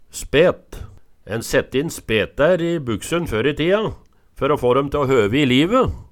spet - Numedalsmål (en-US)